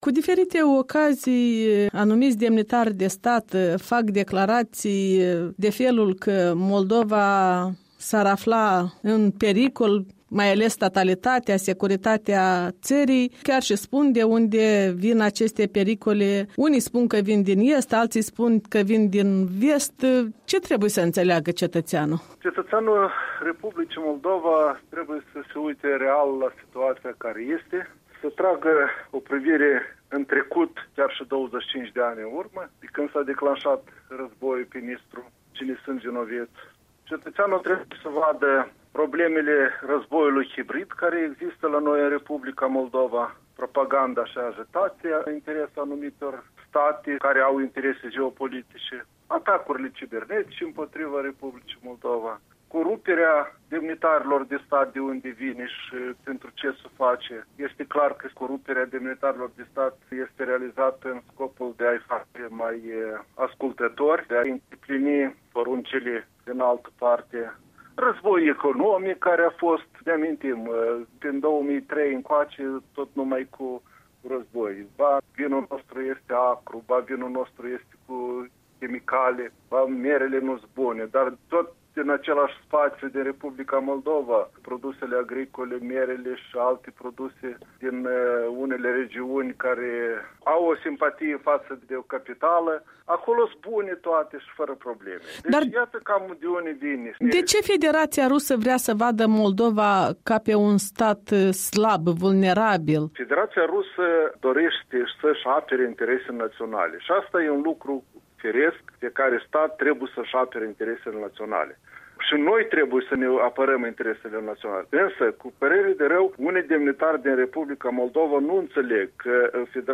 Inteviu cu fostul director adjunct al SIS Valentin Dediu